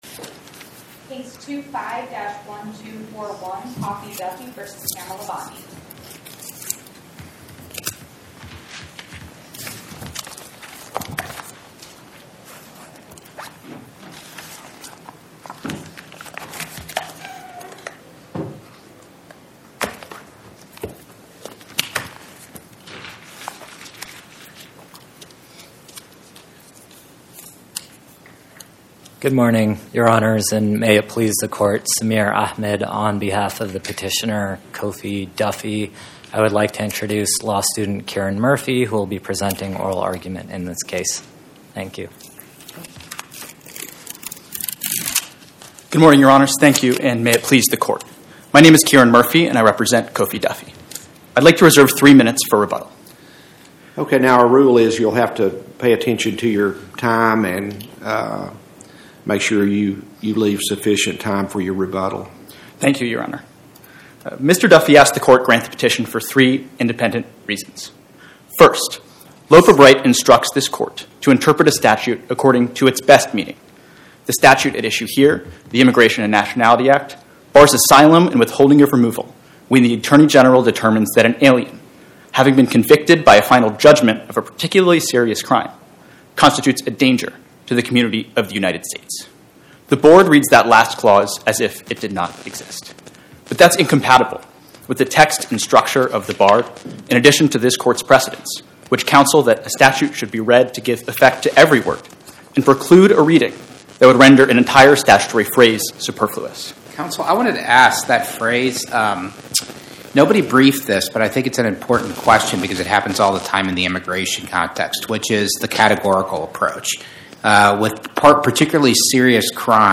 Oral argument argued before the Eighth Circuit U.S. Court of Appeals on or about 01/15/2026